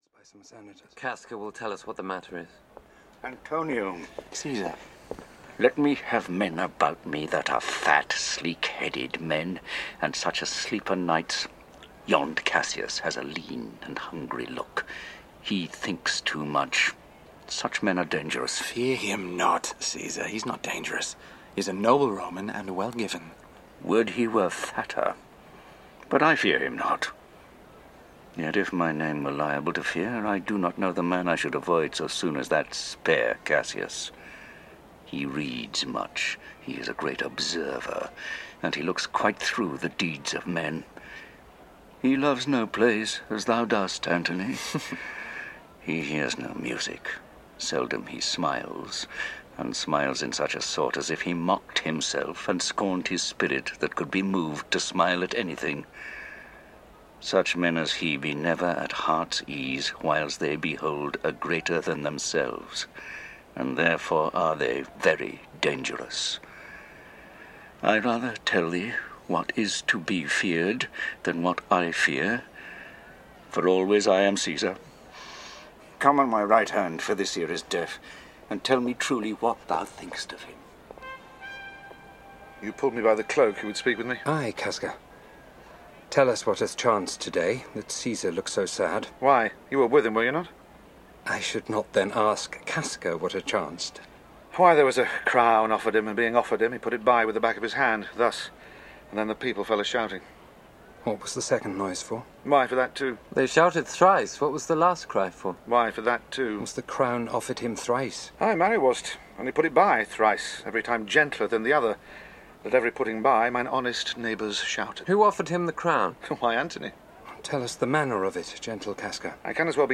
Julius Caesar (EN) audiokniha
Ukázka z knihy